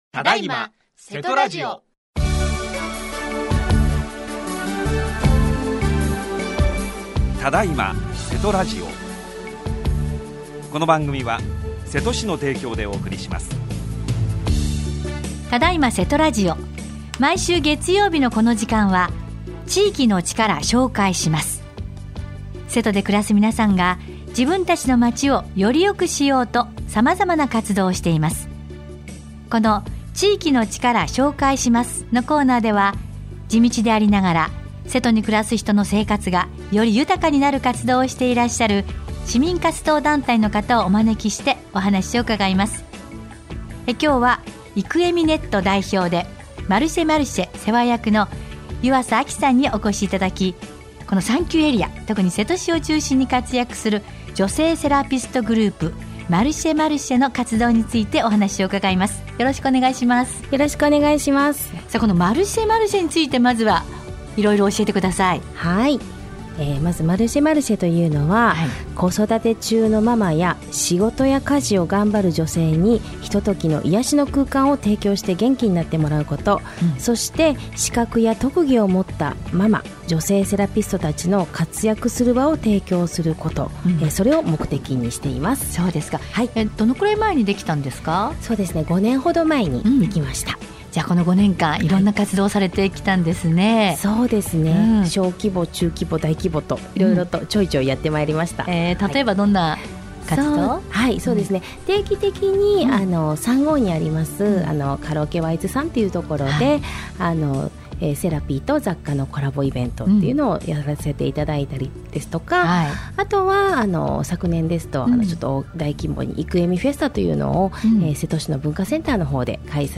28年2月1日（月） 毎週月曜日のこの時間は、〝地域の力 紹介します〝 このコーナーでは、地道でありながら、 瀬戸に暮らす人の生活がより豊かになる活動をしていらっしゃる 市民活動団体の方をお招きしてお話を伺います。